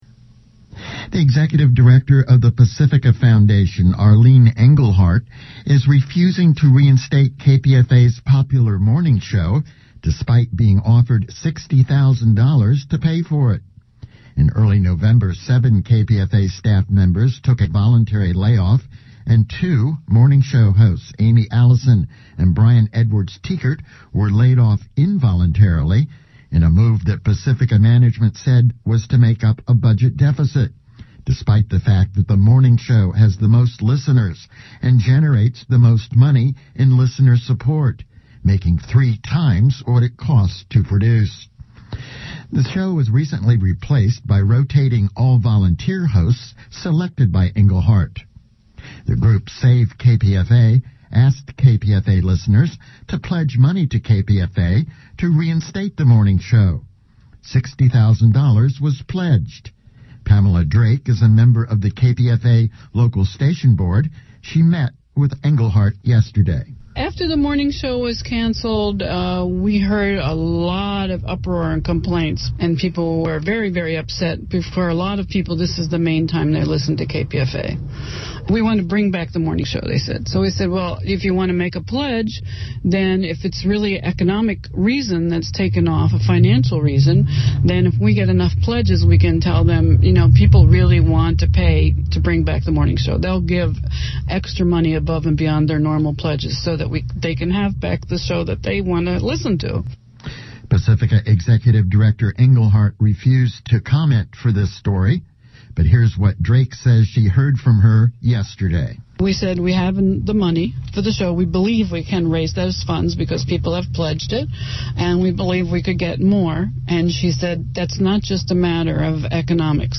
no_news_report__kpfa__1-23-11.mp3